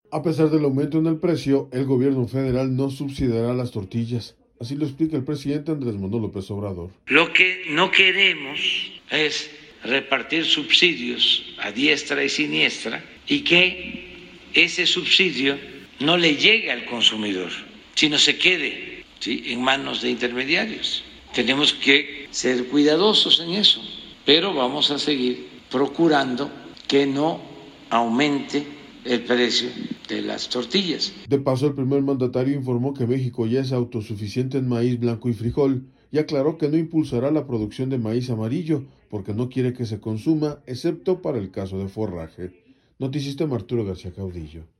A pesar del aumento en el precio, el Gobierno Federal no subsidiará las tortillas, así lo explica el presidente Andrés Manuel López Obrador.